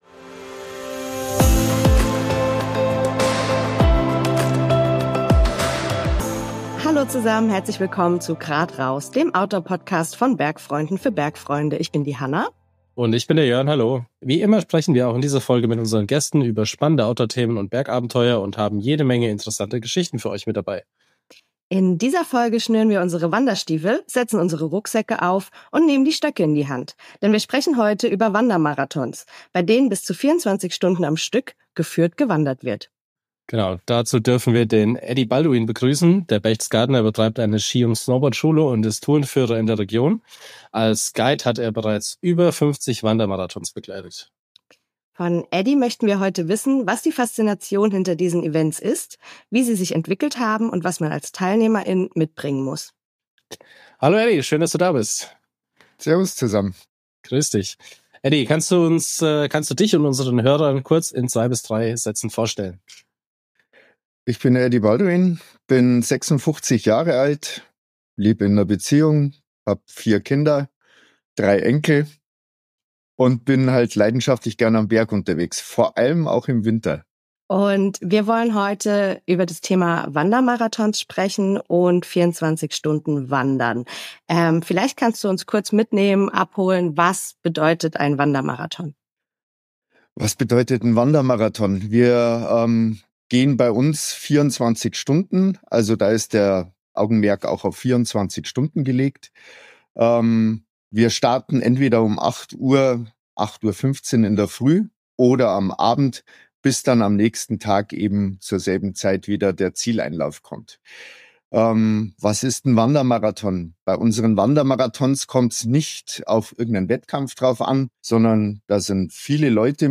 Im Gespräch mit ihm beleuchten wir, wie ein typischer 24-Stunden-Wandermarathon abläuft und was das Event so besonders macht. Unser Gast teilt seine Eindrücke über die einzigartige Atmosphäre, die während dieser langen Wanderungen entsteht, und wie sich die Teilnehmer gegenseitig durch Tiefphasen helfen. Wir erfahren, warum immer mehr Menschen an diesen Marathons teilnehmen und welche Trends und Veränderungen es in der Planung und Durchführung dieser Events gibt.